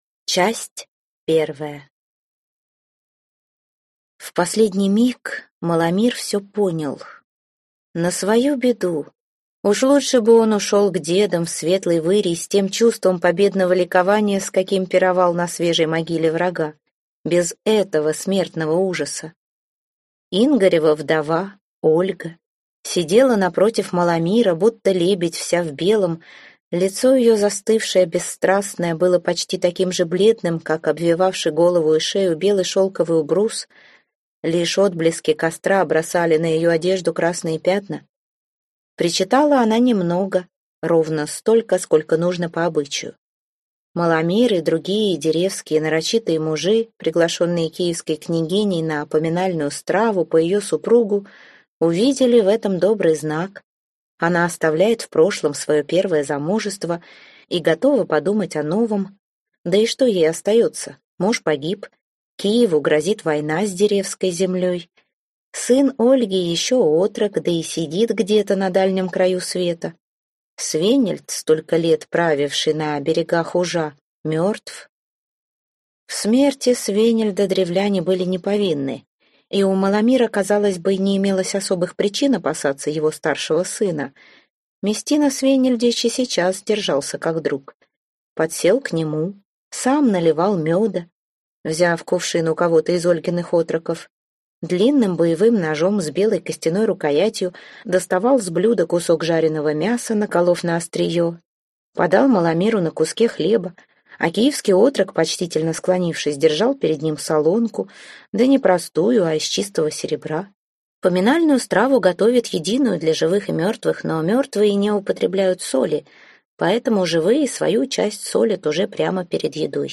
Аудиокнига Княгиня Ольга. Огненные птицы | Библиотека аудиокниг